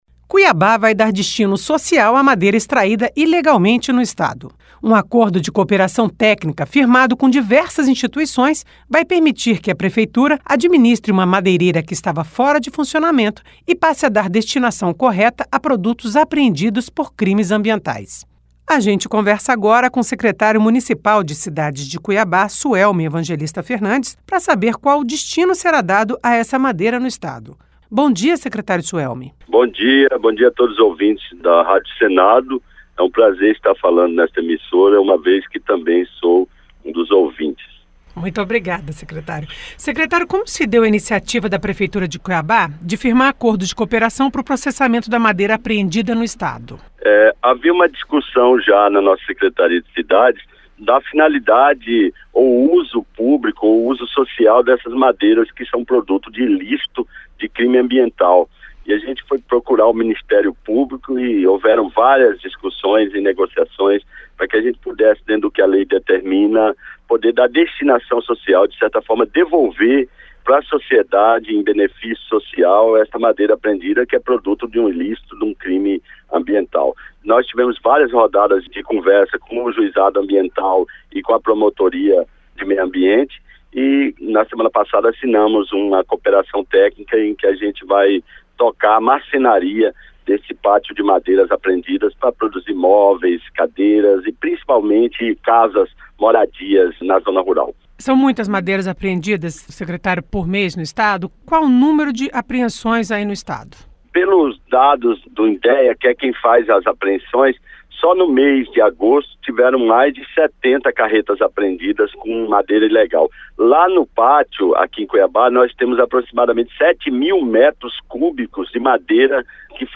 Entrevista com Suelme Evangelista, secretário municipal de Cidades de Cuabá.